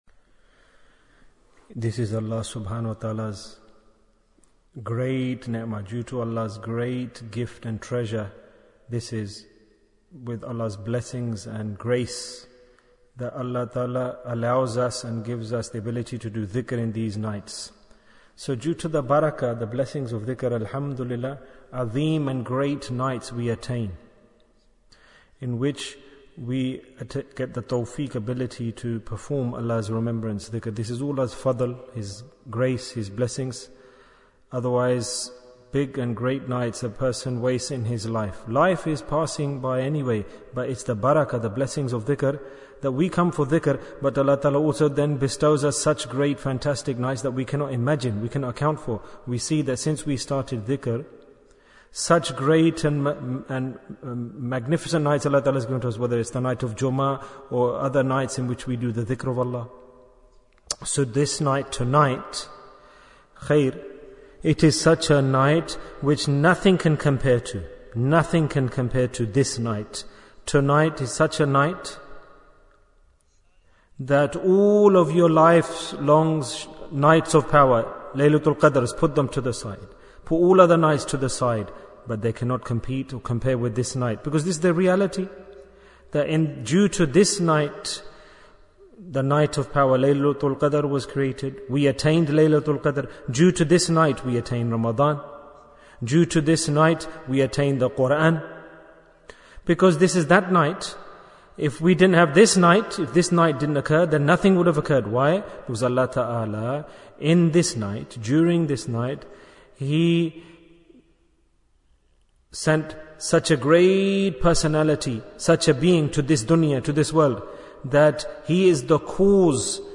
Night of 12th Rabi-ul-Awwal Bayan, 5 minutes18th October, 2021